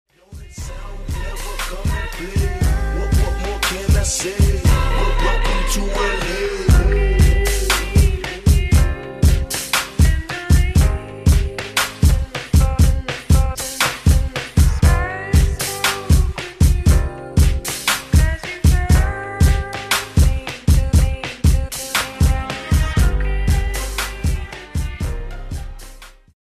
slowed version!